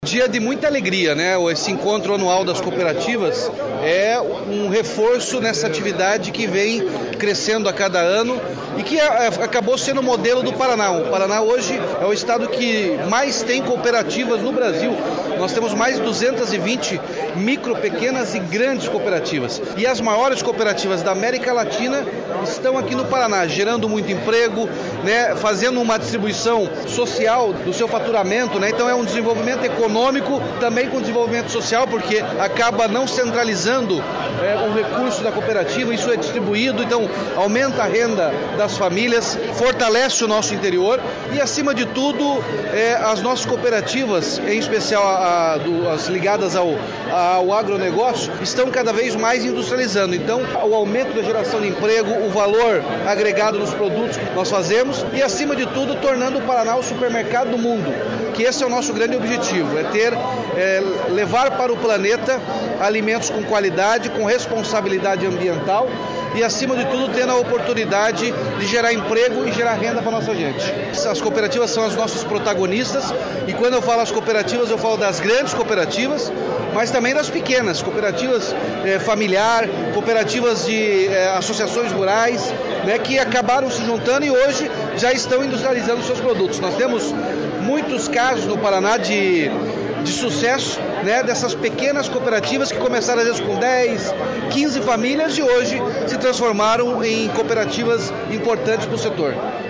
Sonora do governador Ratinho Junior sobre a força das cooperativas do Paraná
RATINHO JUNIOR - ENCONTRO COOPERATIVAS.mp3